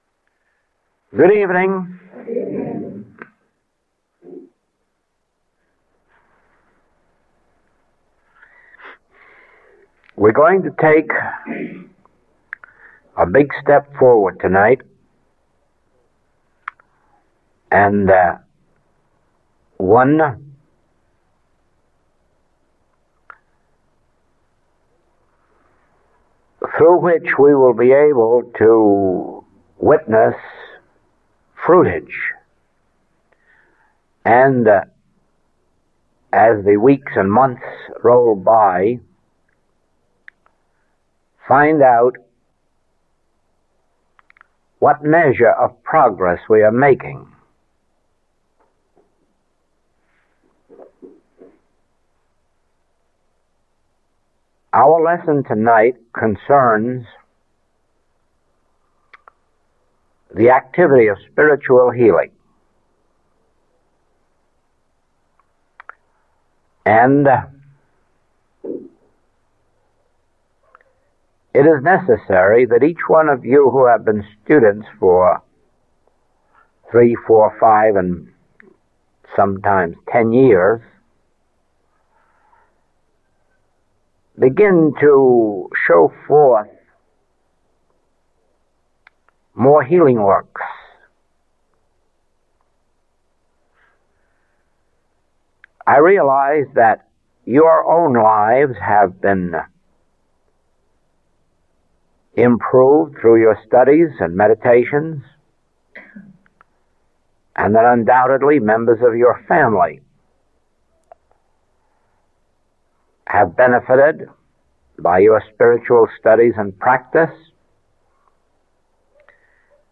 Recording 245B is from the 1959 Maui Advanced Work.